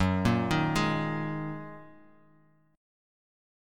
Listen to F#6add9 strummed